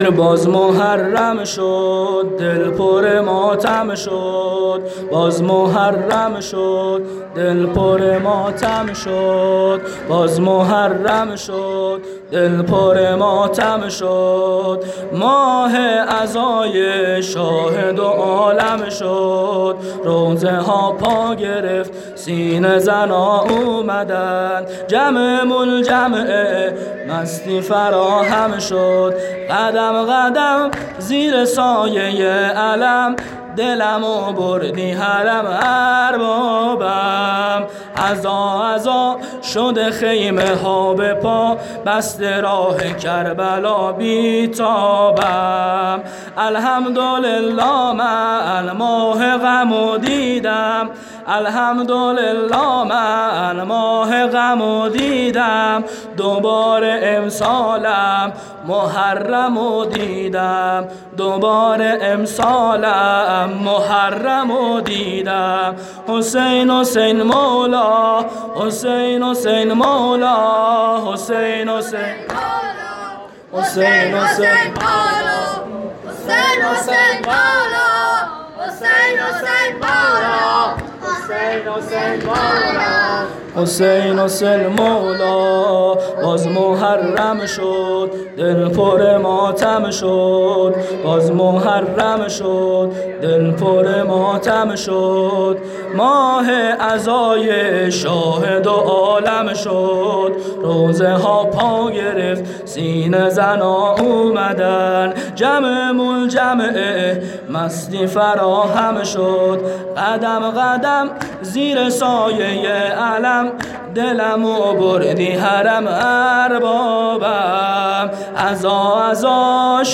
شب اول محرم98 هیئت میثاق الحسین (ع) سیستان